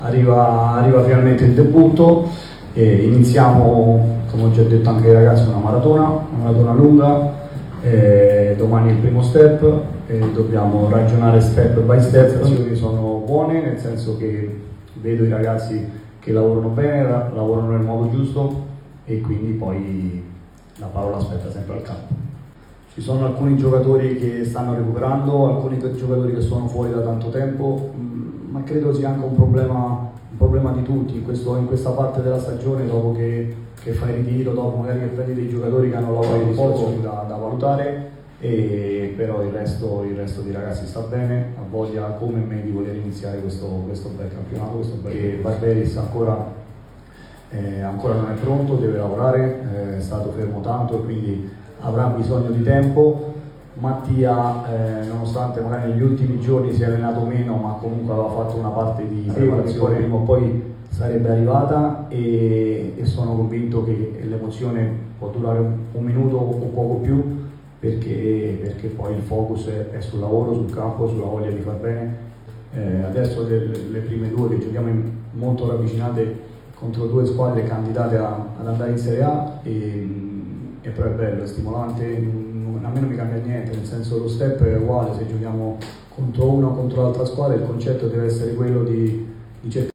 Un estratto delle dichiarazioni di Alberto Aquilani alla vigilia di Sampdoria-Pisa.